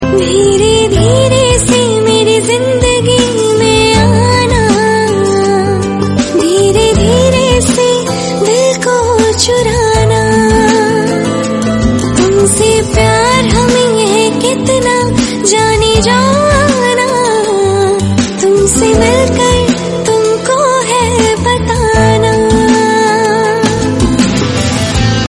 Slowed _ Reverb